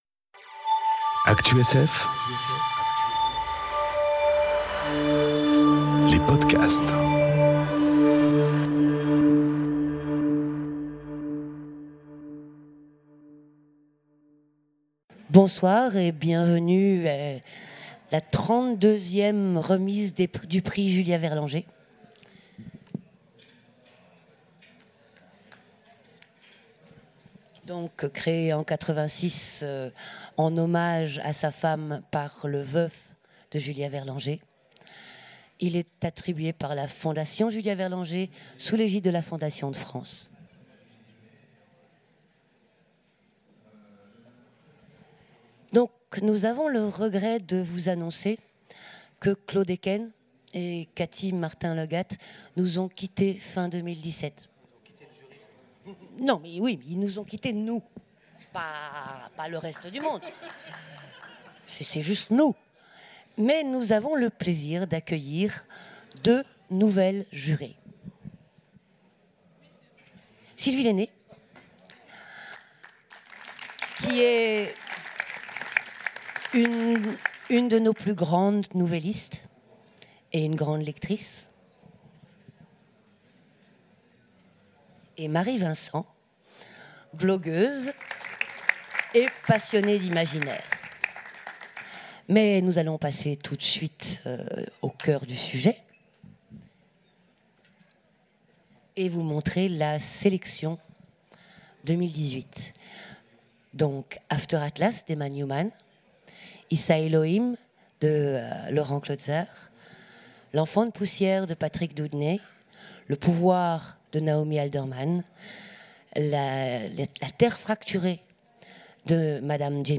Remise du prix Julia Verlanger aux Utopiales 2018
Remise de prix